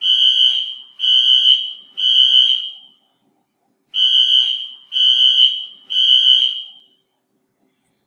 Air alarms play a sound when there is an alarm
airalarm.ogg